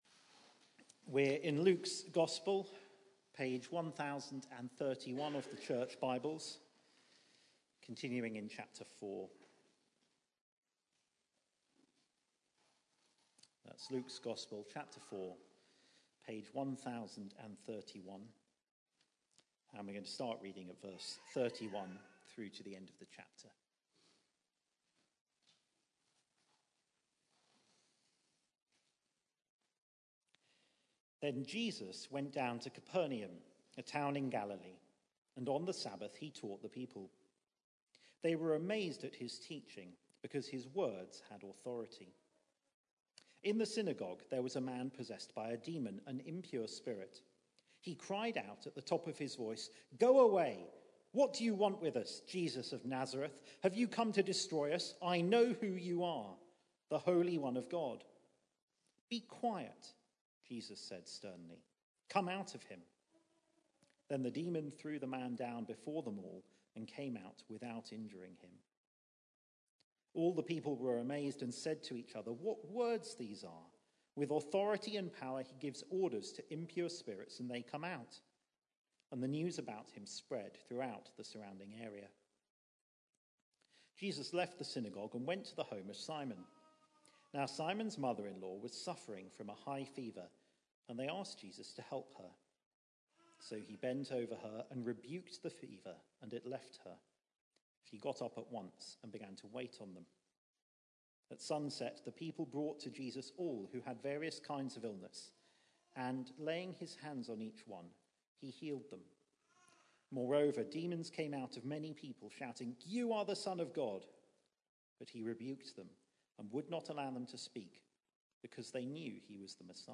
Church at the Green Sunday 4pm
Sermon